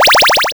bfxr_Pause.wav